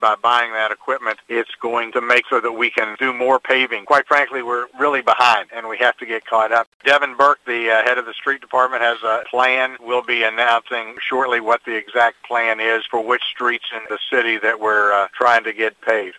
Mayor Ray Morris told WCBC News that the city is preparing a plan for spring and summer paving.